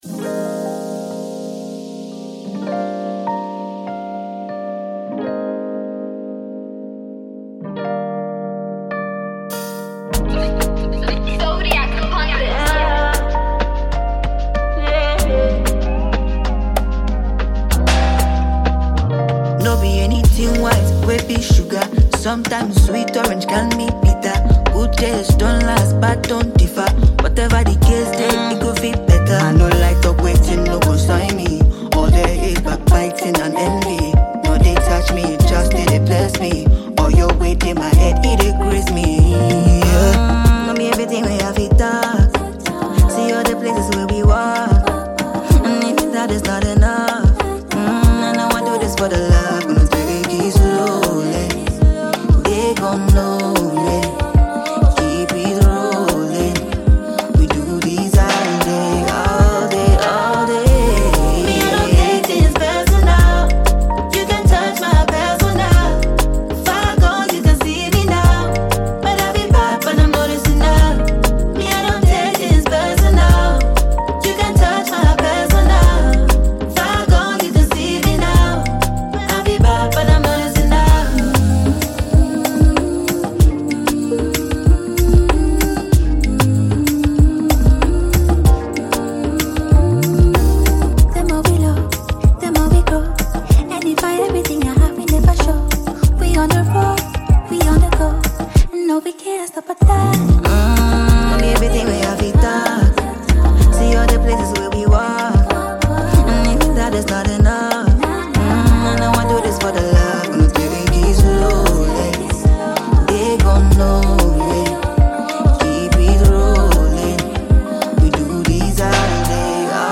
Ghanaian female vocalist and songwriter